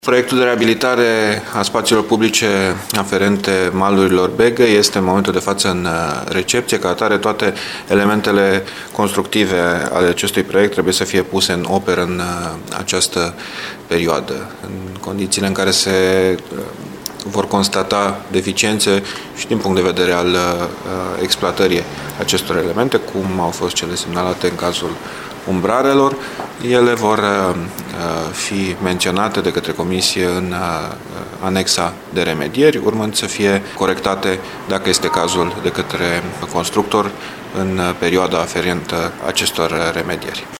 Potrivit viceprimarului Dan Diaconu, lucrarea nu este încă recepționată, iar primăria îi va cere constructorului să remedieze problema, în așa fel încât să se permită scurgerea apei: